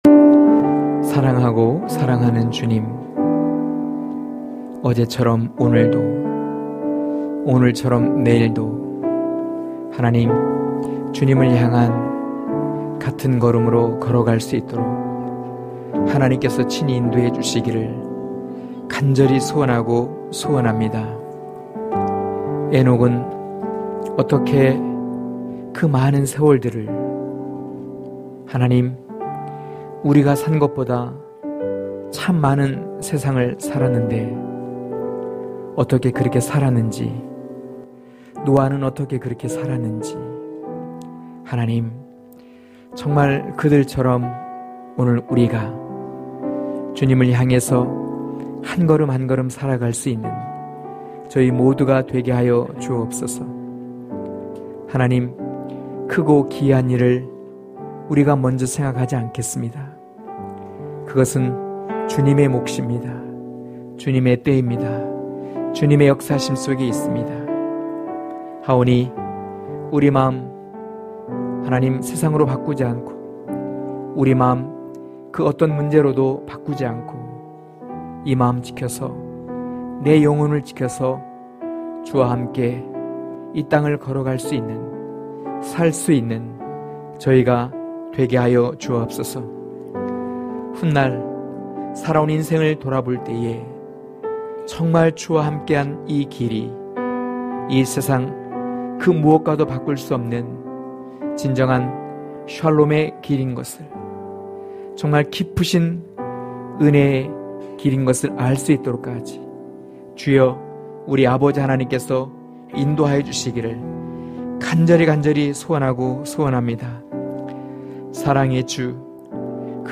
강해설교 - 2.요한을 향한 하나님의 뜻(요일1장5-10절)